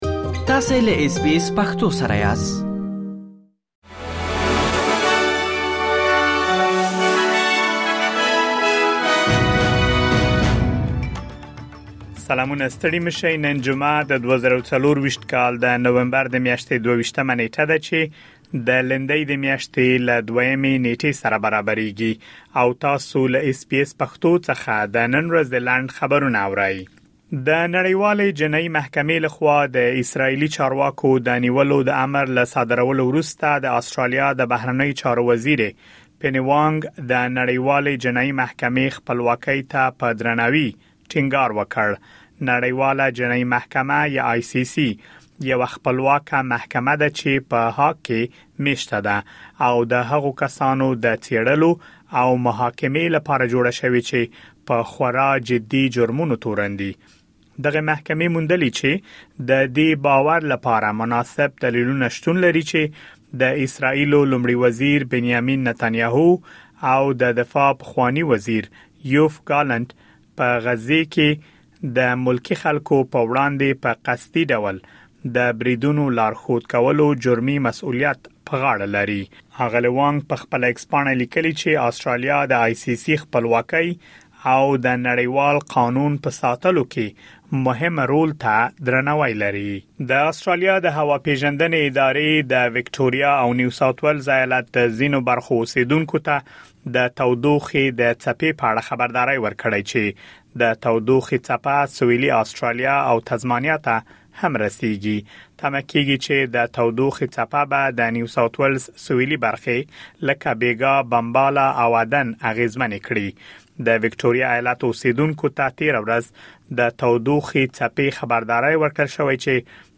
د اس بي اس پښتو د نن ورځې لنډ خبرونه |۲۲ نومبر ۲۰۲۴